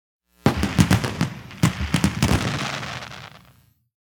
A year or two ago, I recorded the text message sound effect for Happy New Year on iOS.